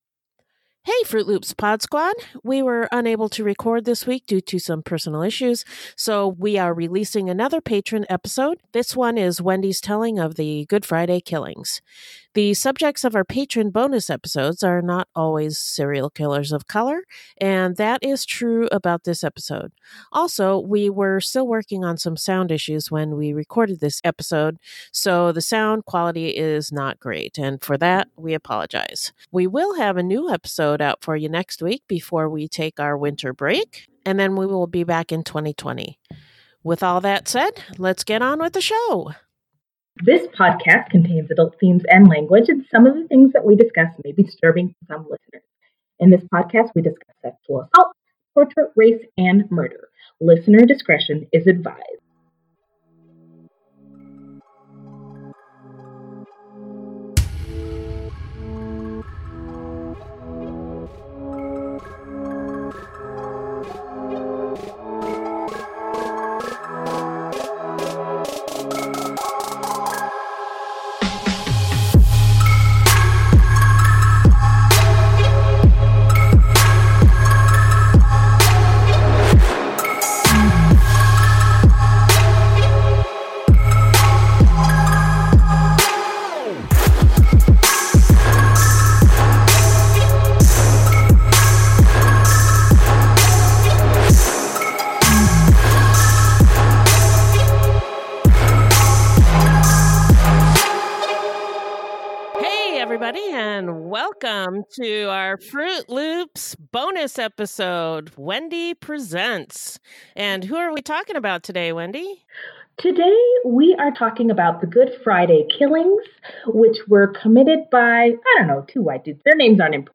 Also, we were still working on some sound issues when we recorded this episode, so the sound quality is not great.